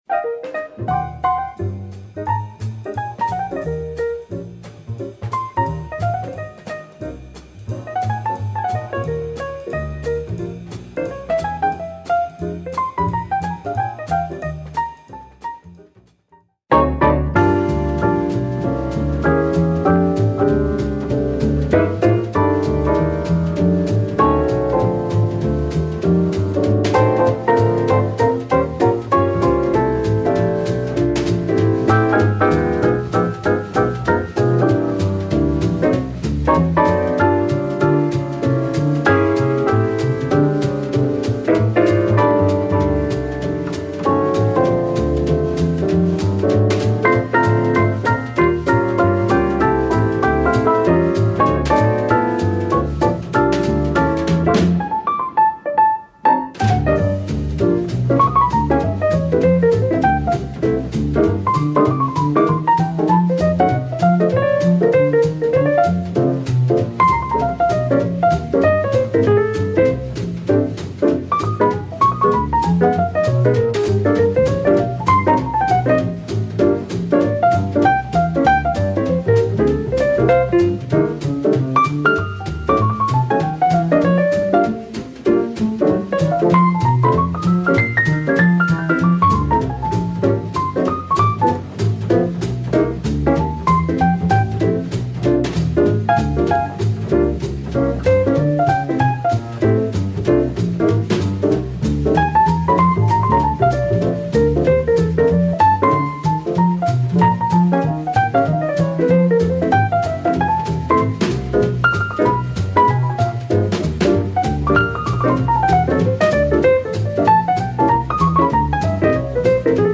tenor sax player
and pianist Red Garland among others...